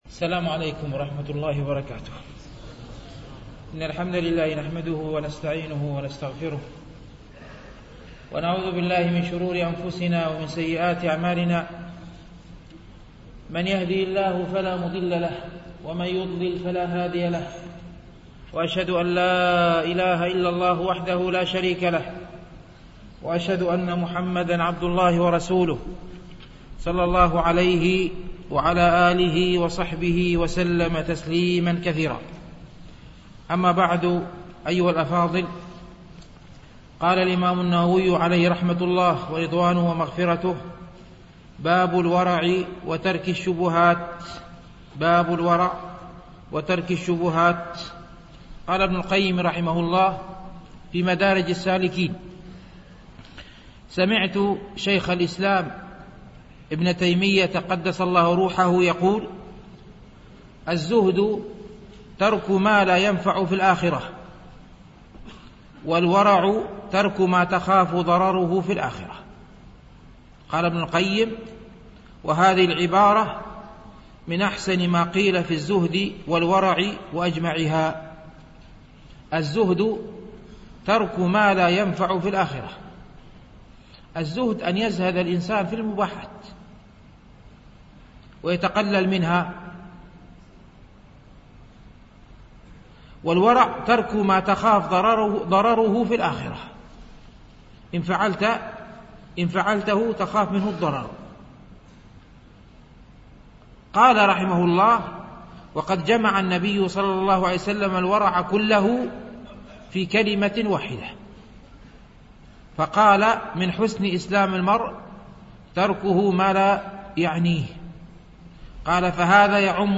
شرح رياض الصالحين - الدرس الحادي والستون بعد المئة
MP3 Mono 22kHz 32Kbps (CBR)